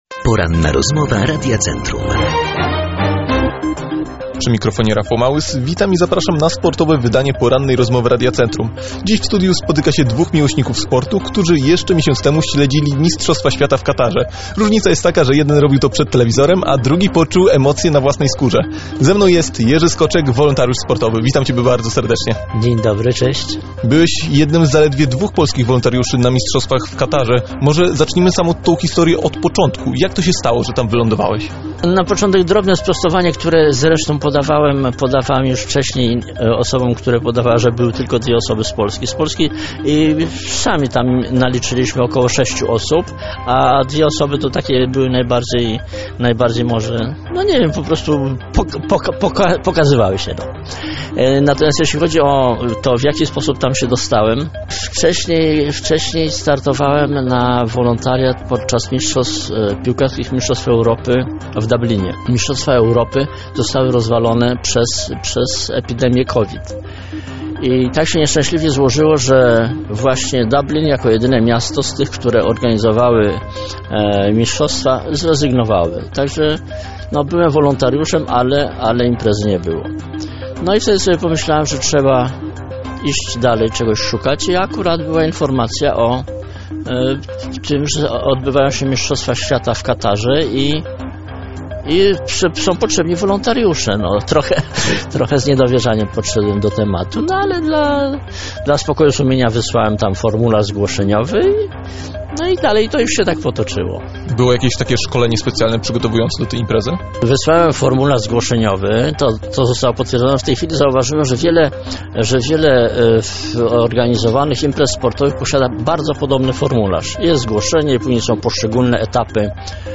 ROZMOWA
ROZMOWA-1.mp3